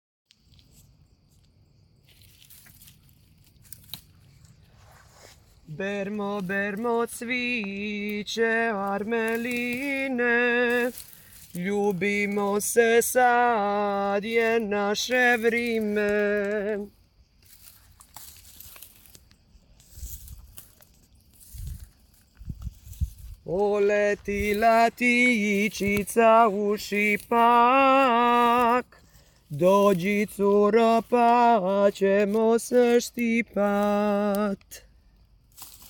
These melodies helped make the laborious task easier. The song’s rhythmic cadence synchronises the movements of the workers, turning a repetitive task into a communal experience.
Designed as a collection of traditional tunes in modern a cappella arrangements, the songs convey women’s labor and daily routines with warmth and humor.